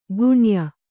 ♪ guṇya